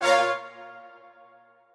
strings5_41.ogg